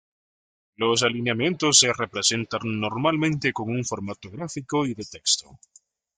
Pronounced as (IPA) /ˈteɡsto/